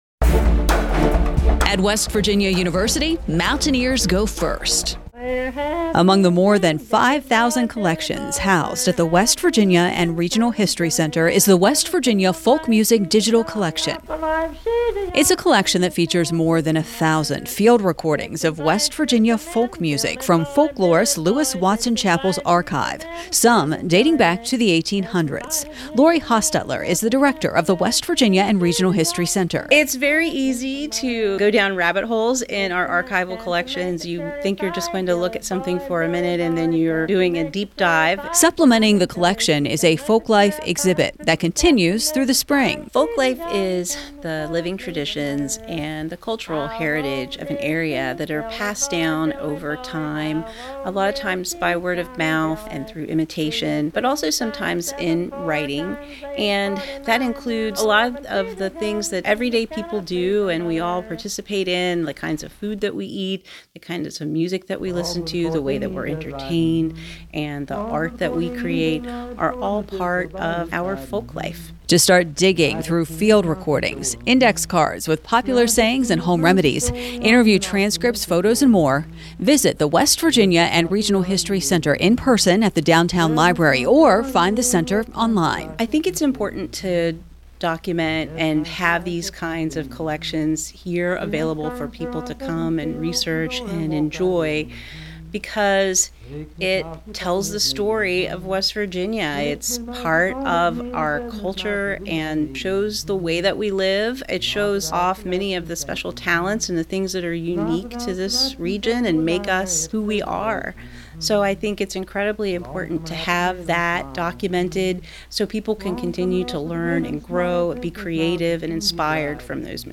Folk Music radio spot